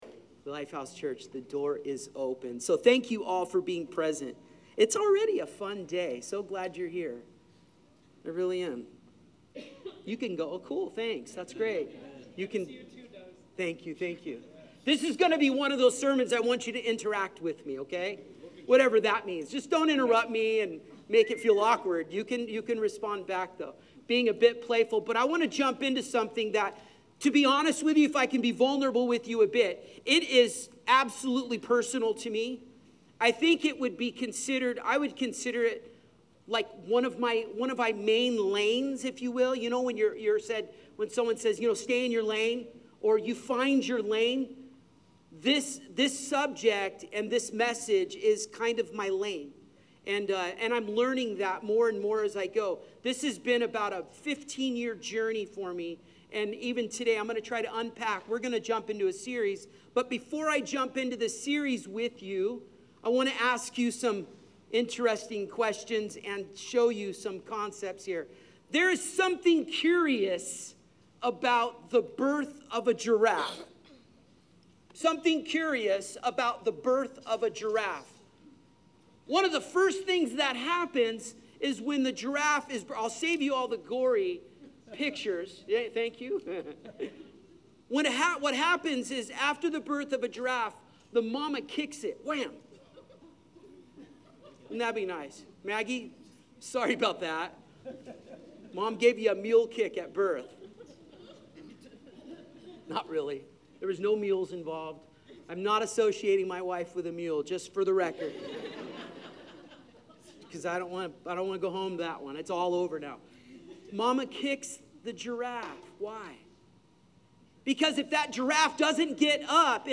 Sermon Series: God Meant It for Good — The Journey of Joseph